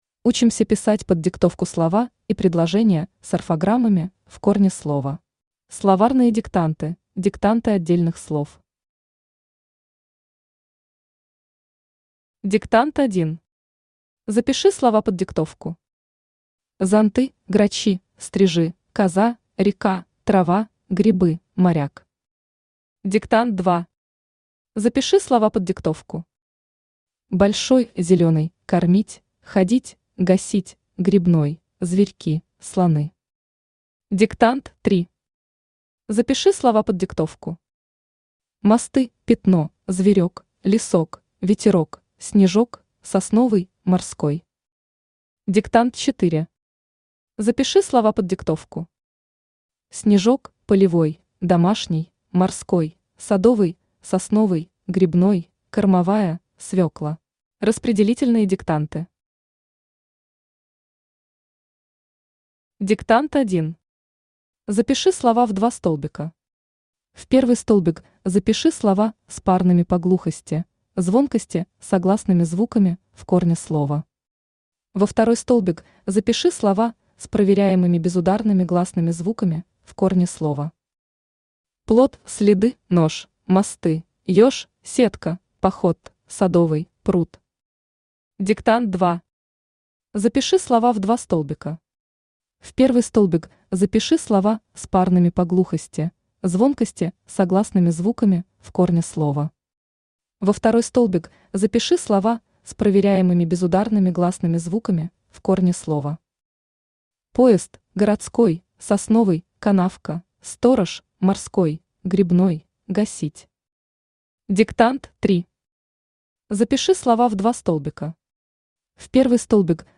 Аудиокнига Учусь писать диктанты. 4 класс | Библиотека аудиокниг
Aудиокнига Учусь писать диктанты. 4 класс Автор Татьяна Владимировна Векшина Читает аудиокнигу Авточтец ЛитРес.